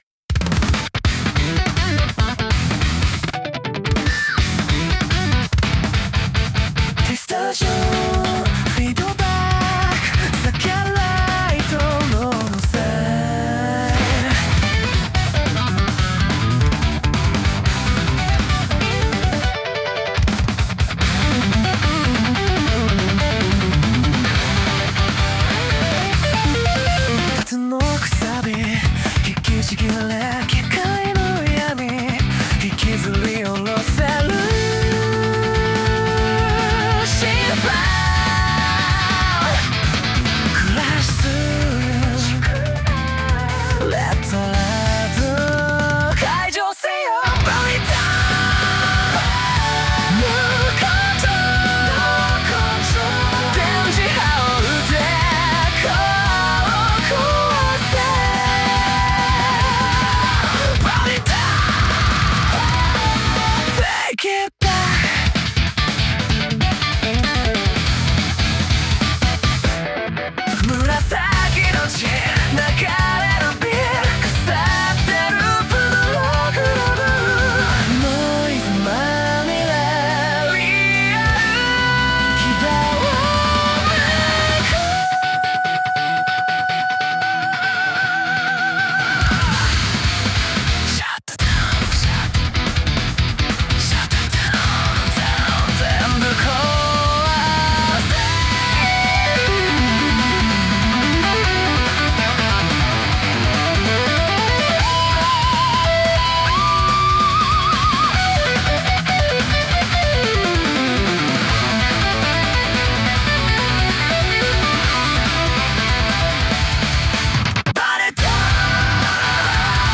男性ボーカル